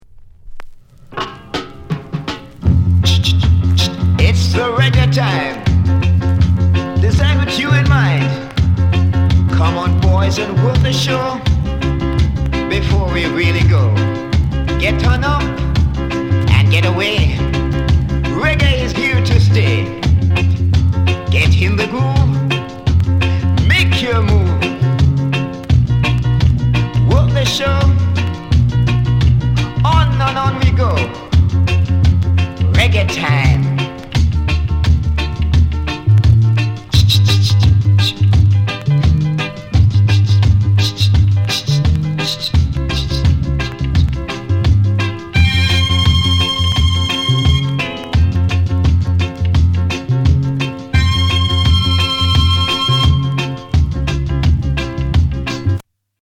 SOUND CONDITION A SIDE VG
NICE ROCKSTEADY